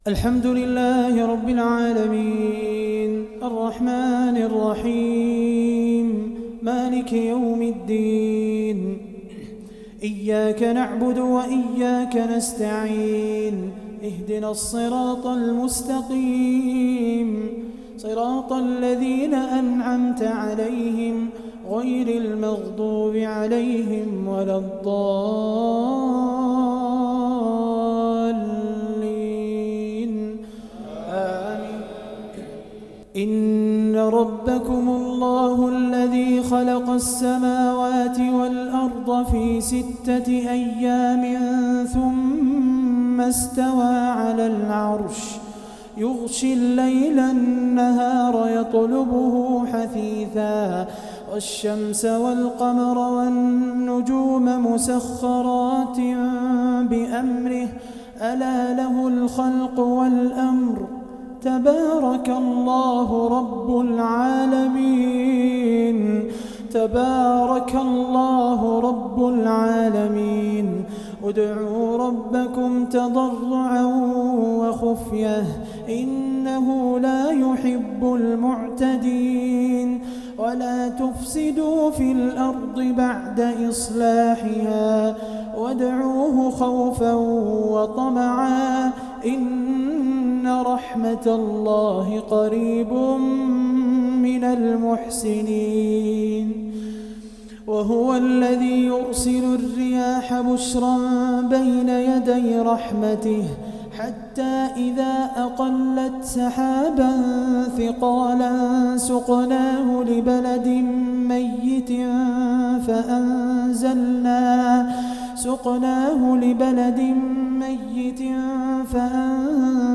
تلاوة حزينة من سورة الأعراف